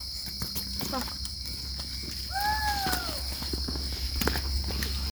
Tawny-browed Owl (Pulsatrix koeniswaldiana)
Province / Department: Misiones
Location or protected area: Santa Ana
Condition: Wild
Certainty: Photographed, Recorded vocal